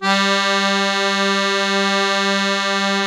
MUSETTE 1 .1.wav